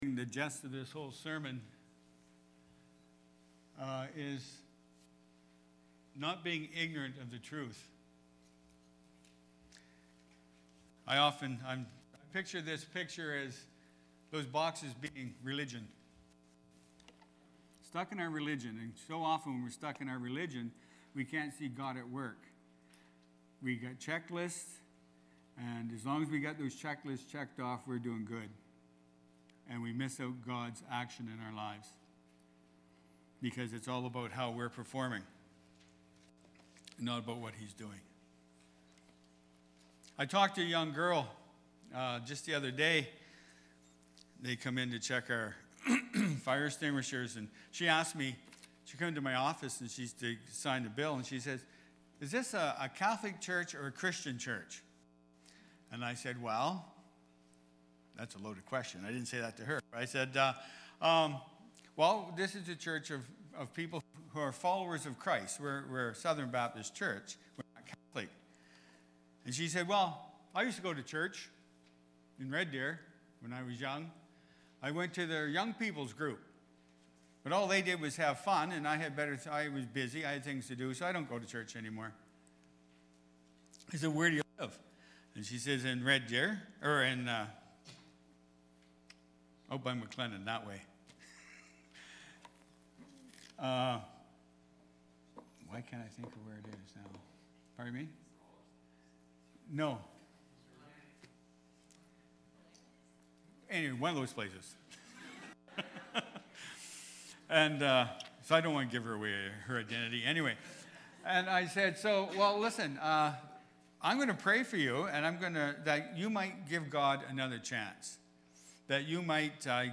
Palm-Sunday-Sermon-Audio.mp3